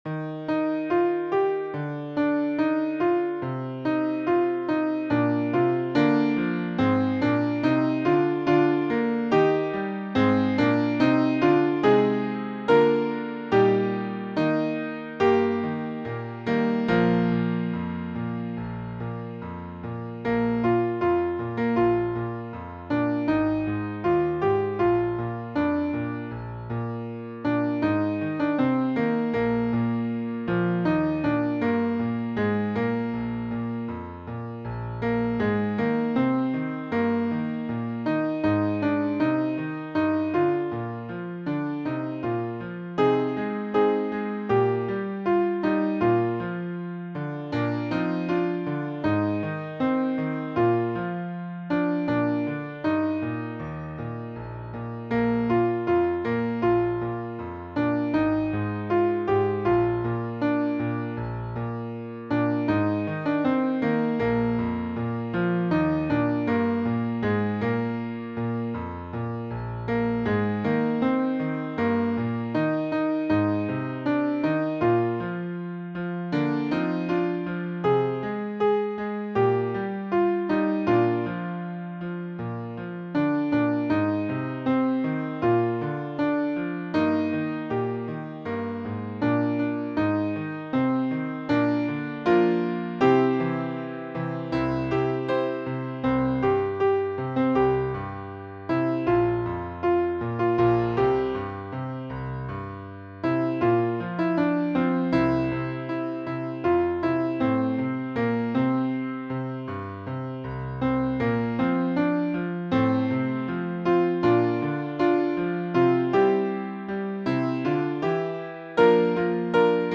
(Musical Gospel)